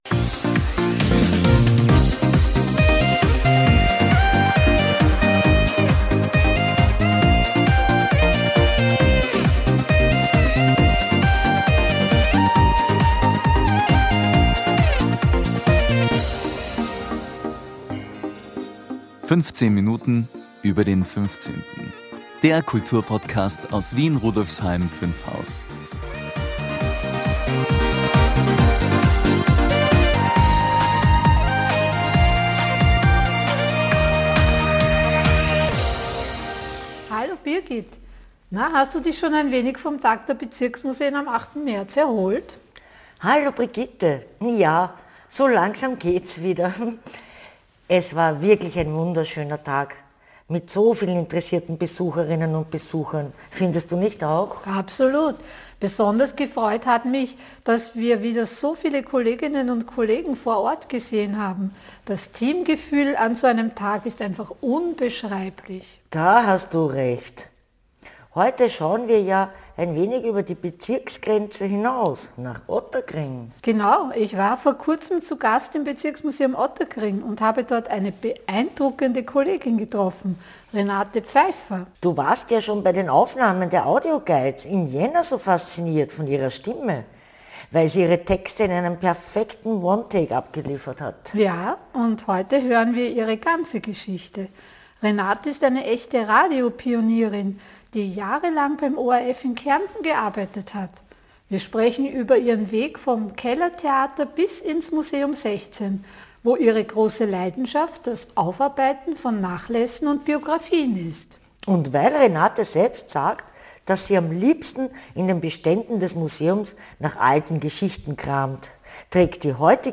Hören Sie rein in eine Folge voller Kultur, Geschichte und Grätzelgefühl!